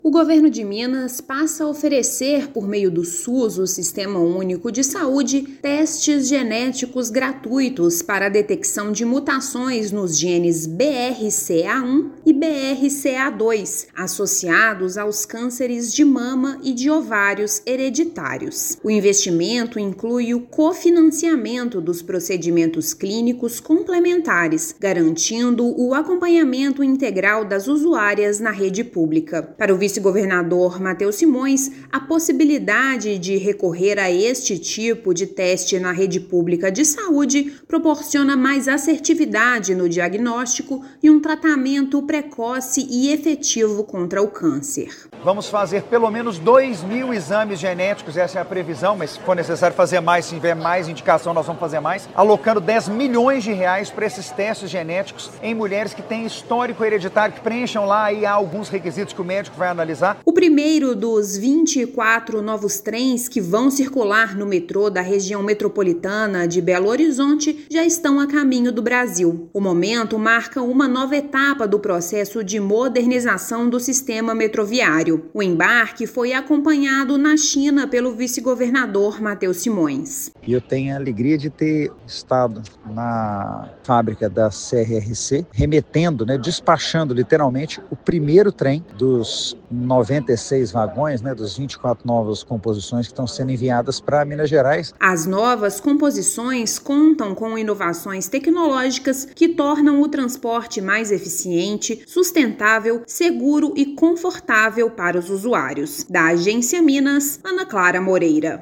Minuto Agência Minas da semana destaca realização de testes genéticos gratuito para detecção de câncer de mama e despacho do primeiro entre os novos trens que vão circular no metrô de BH. Ouça matéria de rádio.